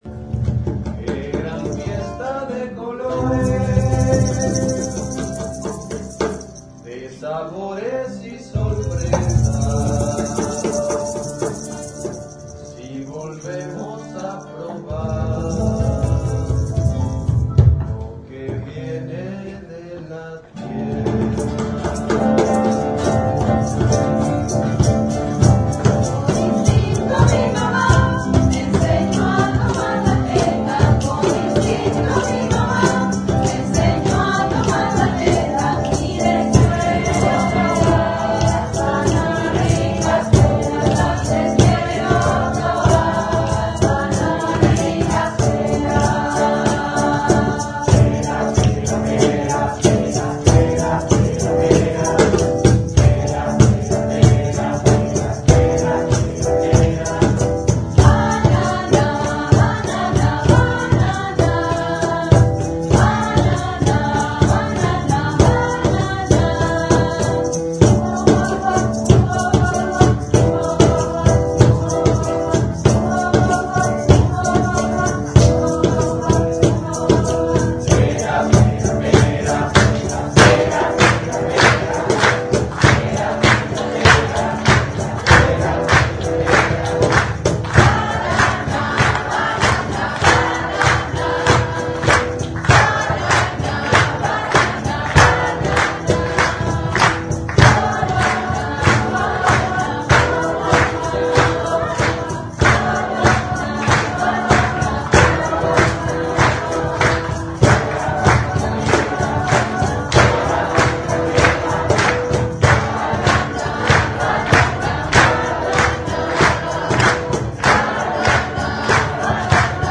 13-coro con público
13-coro-con-publico.mp3